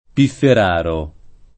pifferaro [ piffer # ro ] → pifferaio